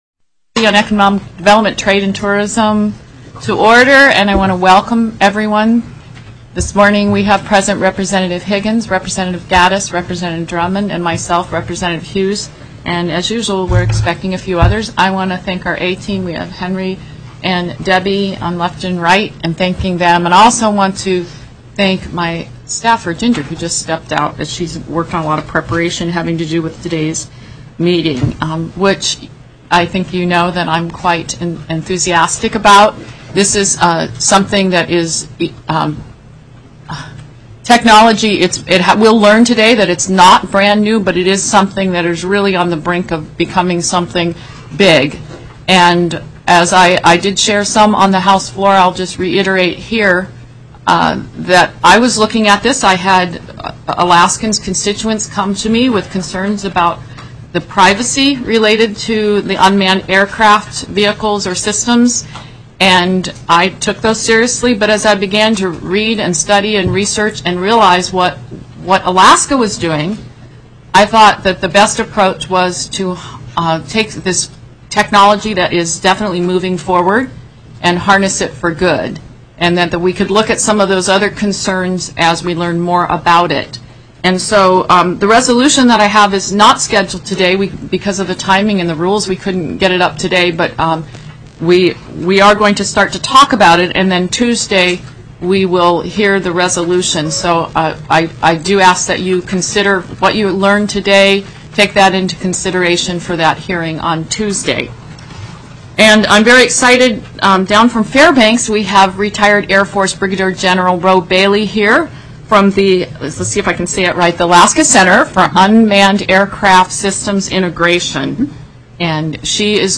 03/21/2013 11:15 AM House ECON. DEV., TRADE & TOURISM